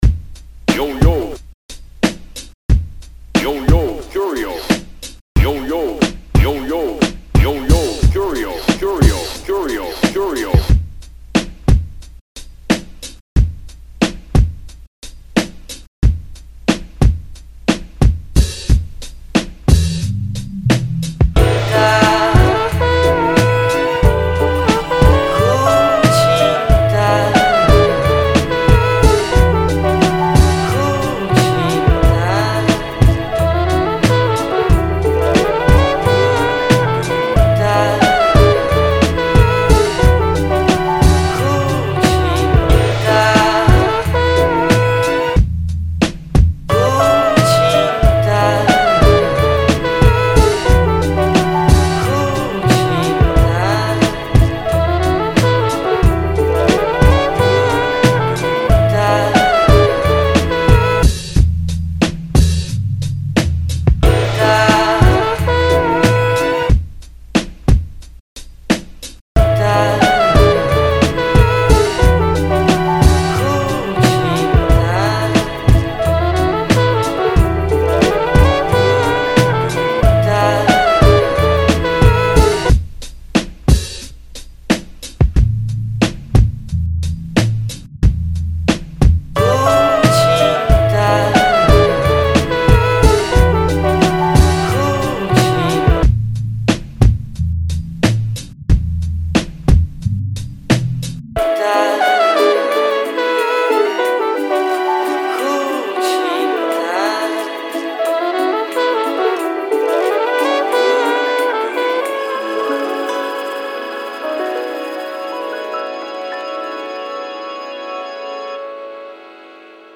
beat making